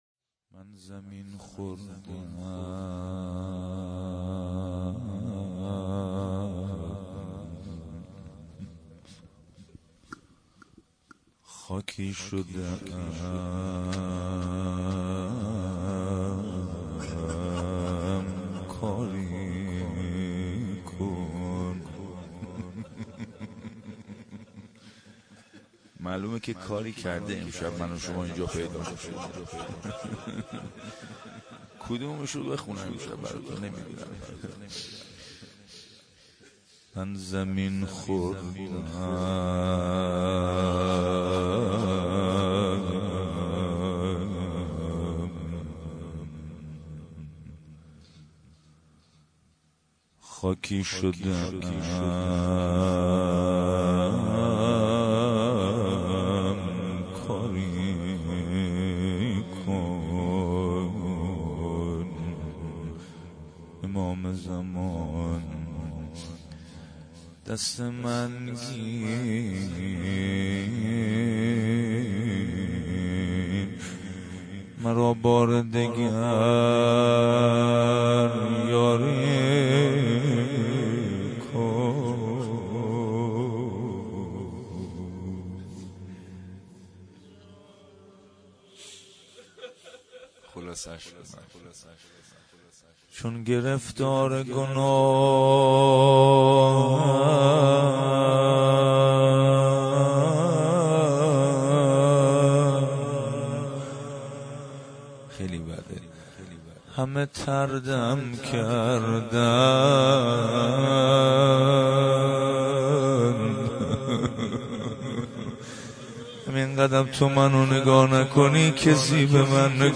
توسل هفتگی-روضه حضرت زهرا(س)-20 بهمن 1396